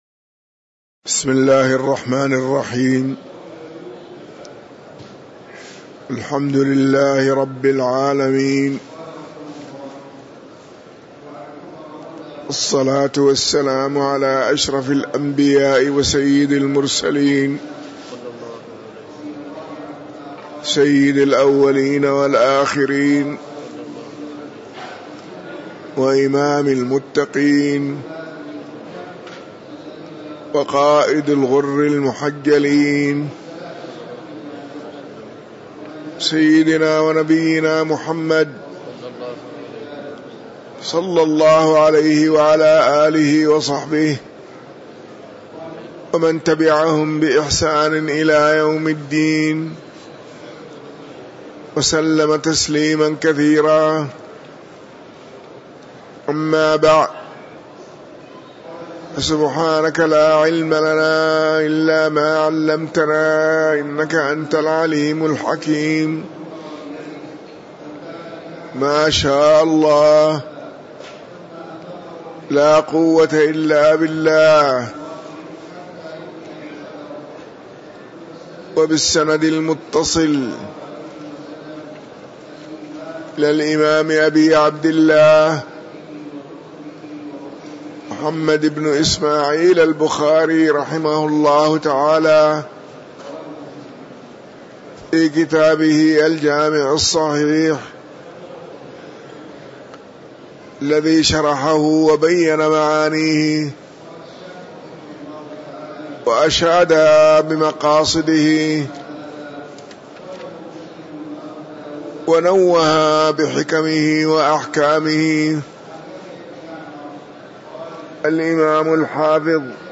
تاريخ النشر ٢٩ ربيع الثاني ١٤٤٤ هـ المكان: المسجد النبوي الشيخ